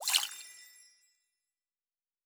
pgs/Assets/Audio/Fantasy Interface Sounds/Potion and Alchemy 07.wav at master
Potion and Alchemy 07.wav